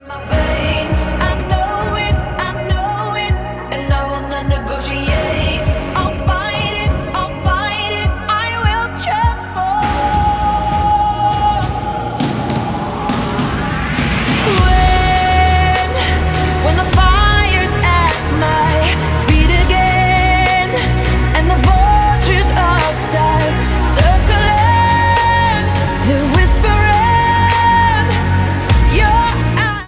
• Pop
American singer
mid-tempo electronic song